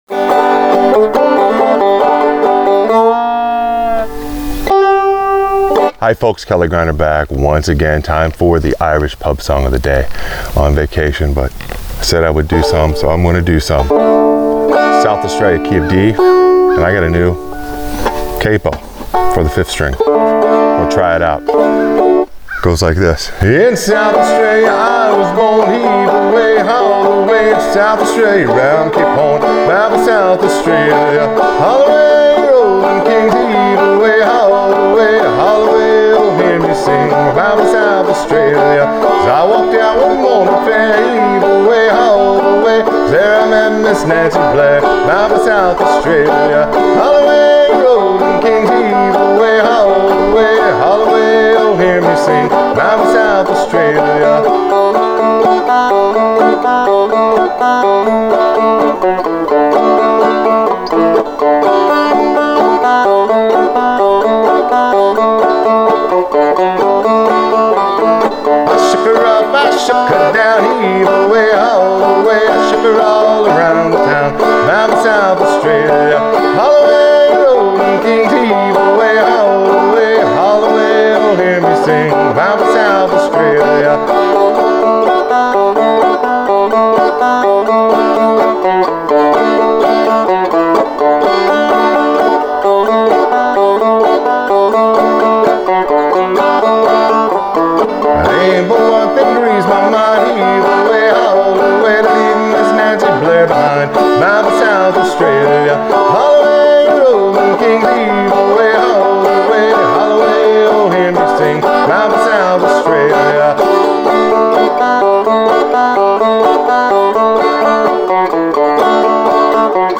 Irish Pub Song Of The Day – Frailing Banjo Lesson: South Australia